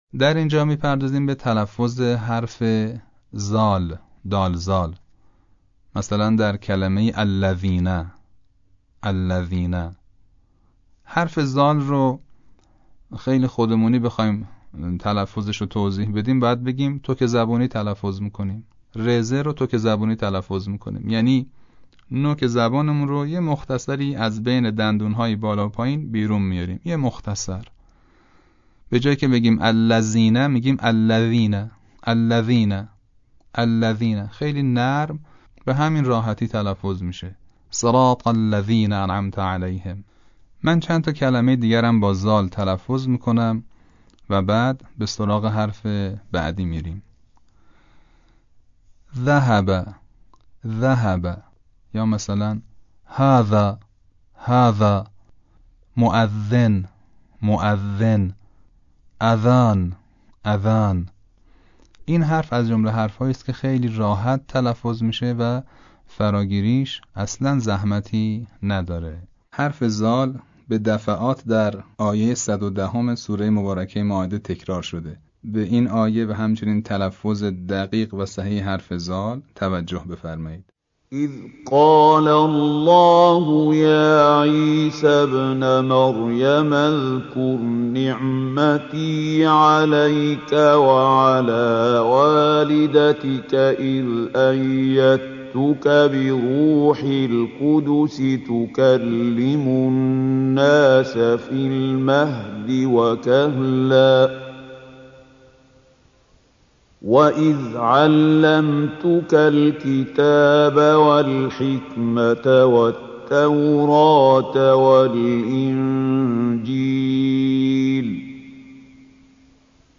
اما در تلاوت فصیح عربی قرآن، از مماس شدن نوک زبان با لبه ی دندان های ثنایای بالا (دندانهای خرگوشی) همراه با خروج هوا، ایجاد می گردند.
مثال های صوتی تلفظ حروف ذال و ثاء